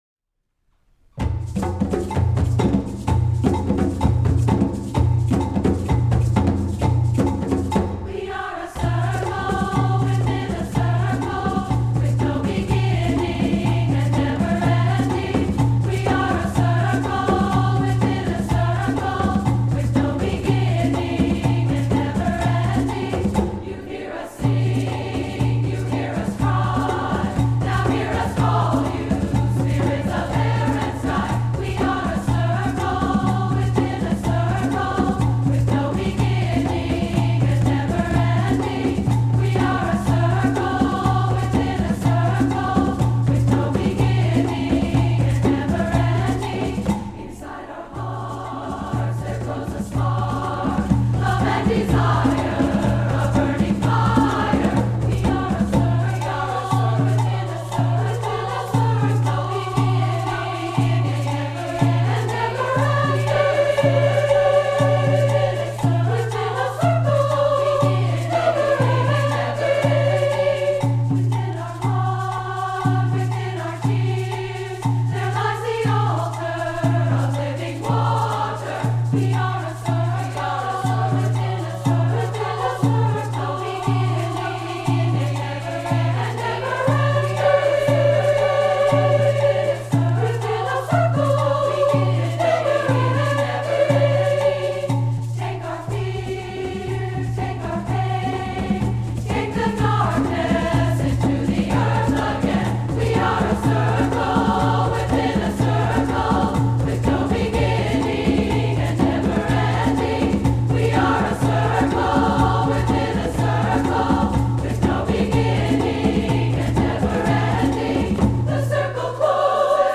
SAA, a cappella, perc
fun , dynamic and energizing — a rousing concert opener